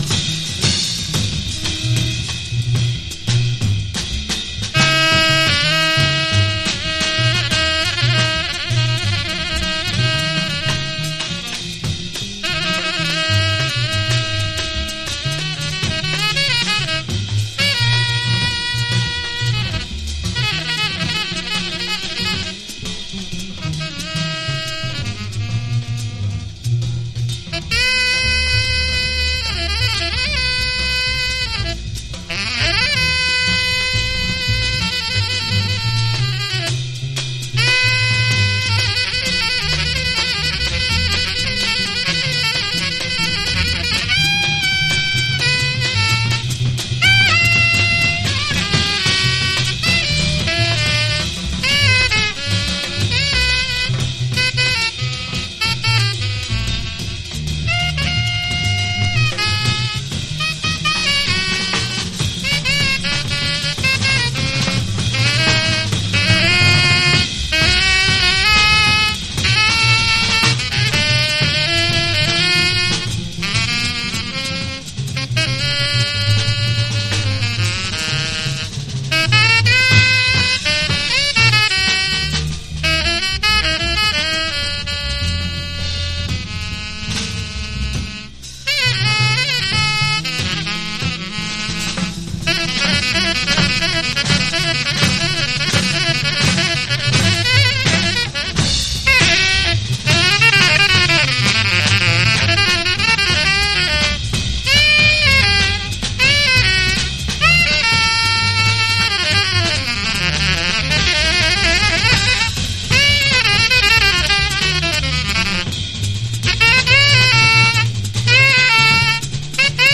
Genre SPIRITUAL JAZZ